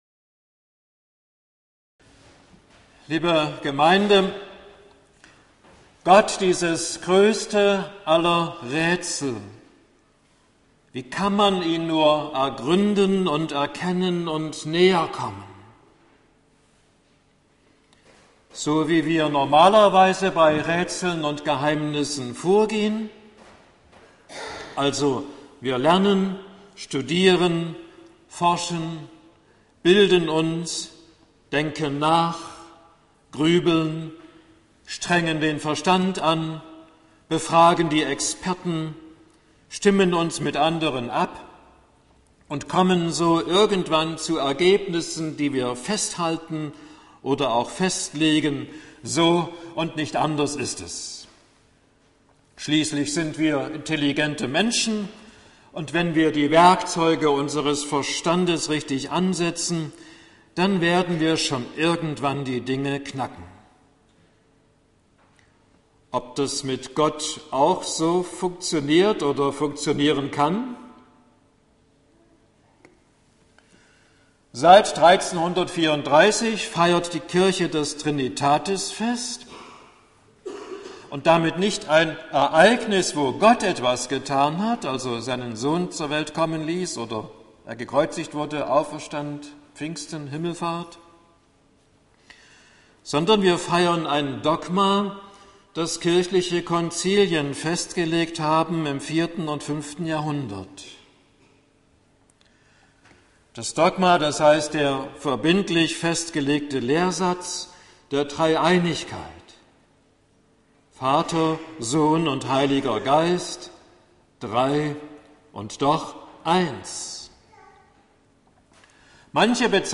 Predigt zu Trinitatis